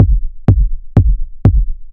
edm-kick-31.wav